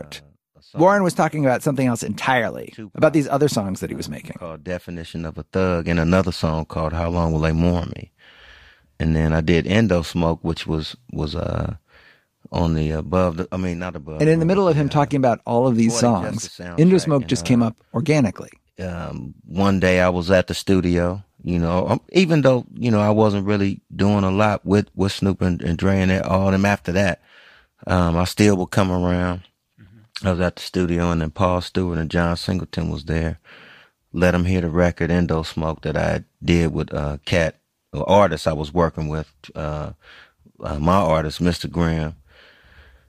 Gimlet co-founder Alex Blumberg shares lessons, gained from 20 years of working in audio, about what it takes to produce a podcast episode that entertains, educates, and connects with listeners on an emotional level.